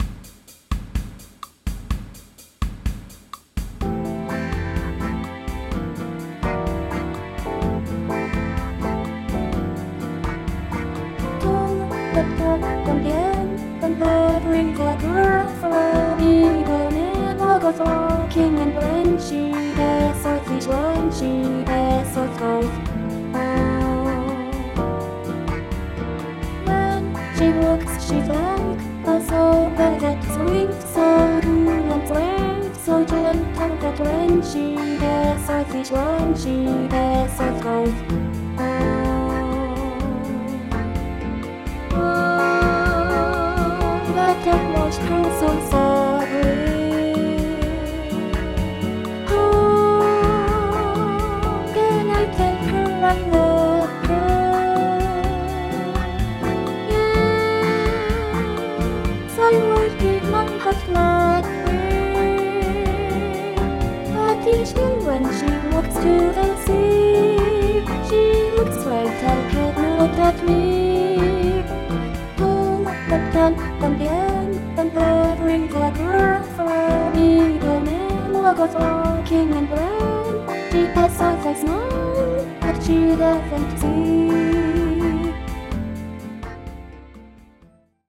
DTMを始めた初期にアレンジの練習として作ったものですが、ボーカルを入れた後に全く別アレンジにしてしまいました。